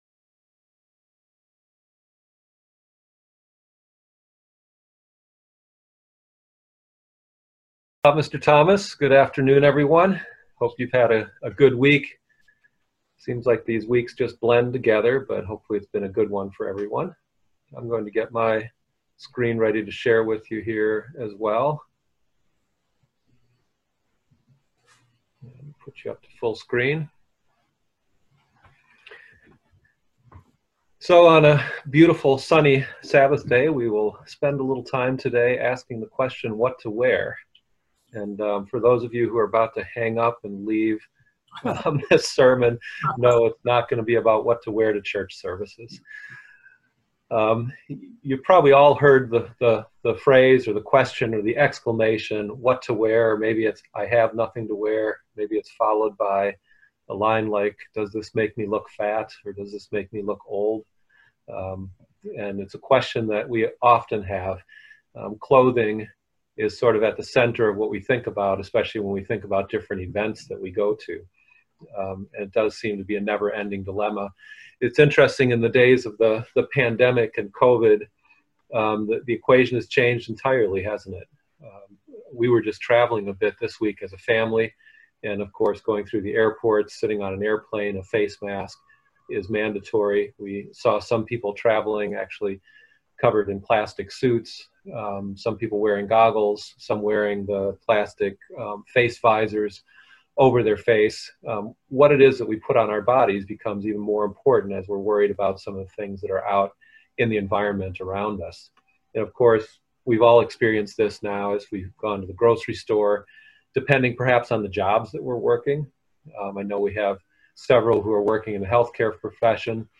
And for those of you who are about to hang up and leave this sermon, know it's not going to be about what to wear to church services.